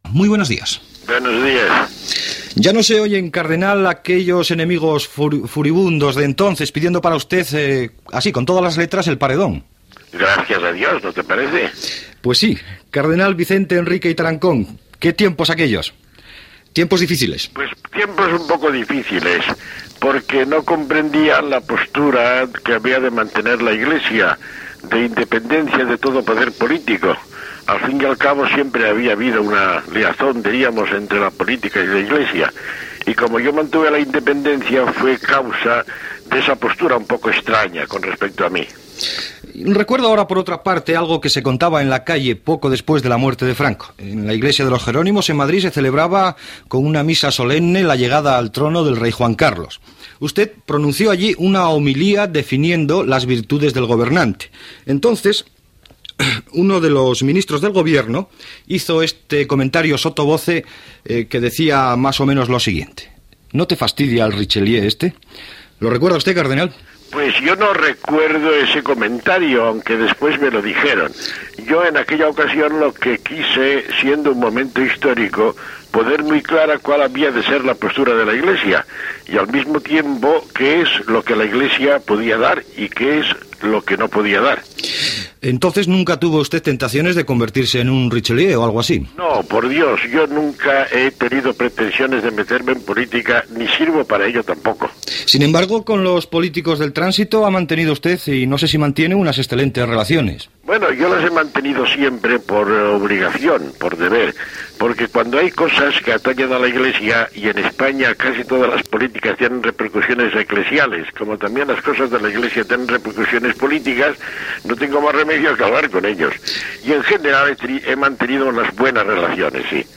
Entrevista al cardenal Vicente Enrique y Tarancón sobre la relació de l'església amb el franquisme i els polítics de la transició, la seva presidència de la Conferència Episcopal i la figura del Sant Pare Joan Pau II
Informatiu